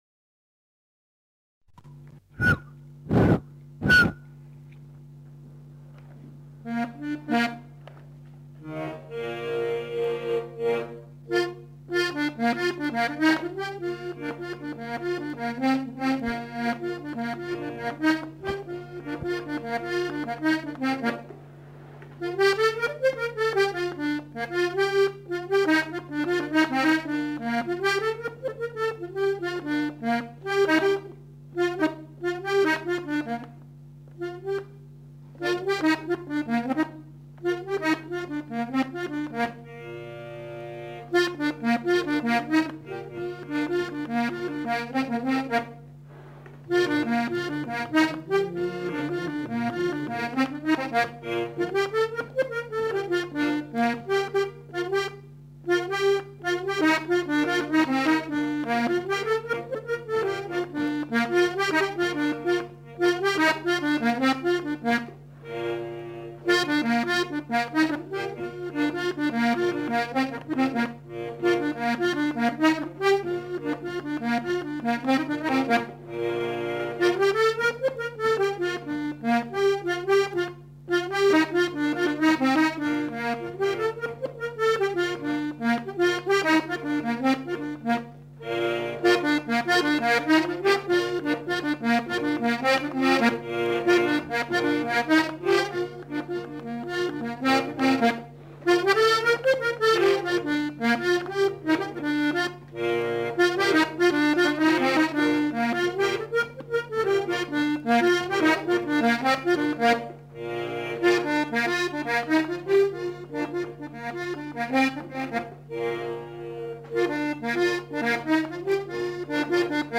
Airs à danser et mélodies interprétés à l'accordéon chromatique
enquêtes sonores